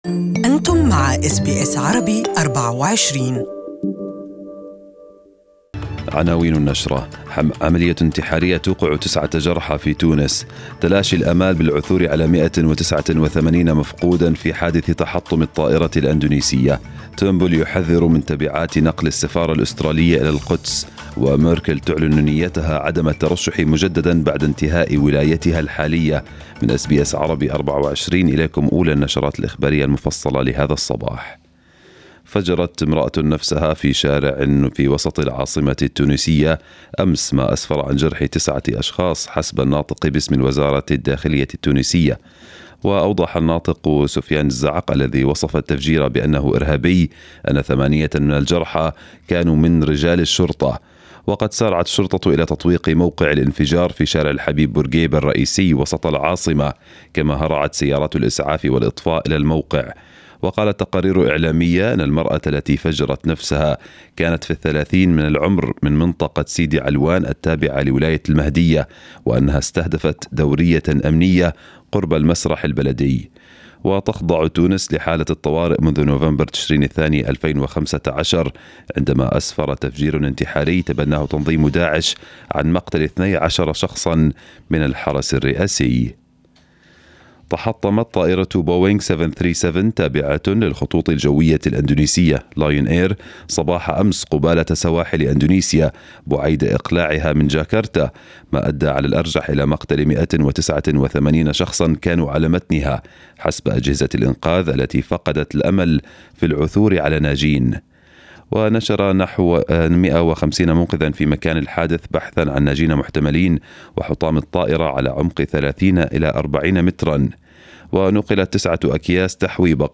News bulletin of the morning